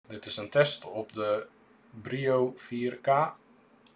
Hieronder heb ik een drietal geluidsopnamen gemaakt met verschillende microfoons om de Quadcast 2 tegen af te meten.
Microfoon van de Brio 4K-webcam:
sample-microfoon-Brio-4K.m4a